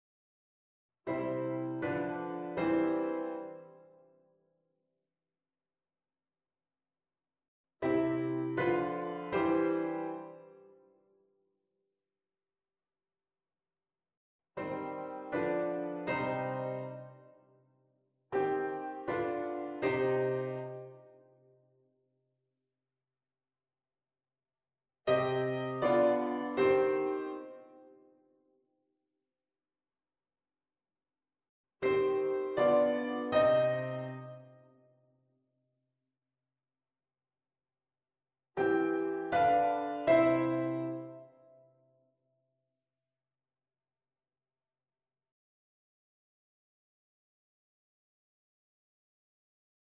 VII6 als doorgangsakkoord in een tonica-prolongatie: